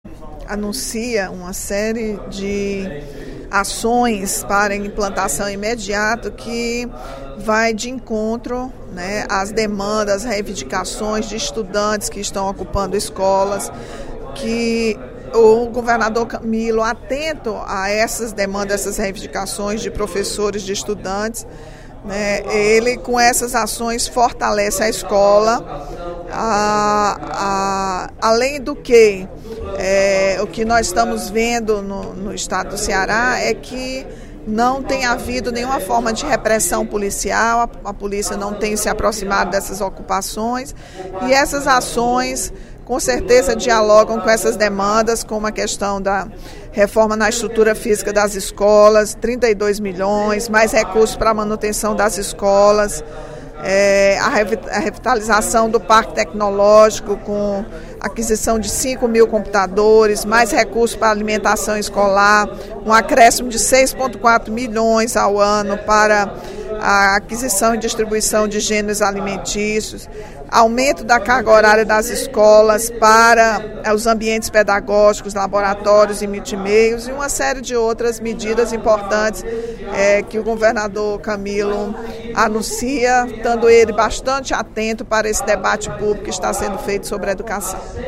A deputada Rachel Marques (PT) destacou, durante o primeiro expediente da sessão plenária desta terça-feira (10/05), o pacote de investimentos para a educação, anunciado pelo governador Camilo Santana na segunda-feira (09/05).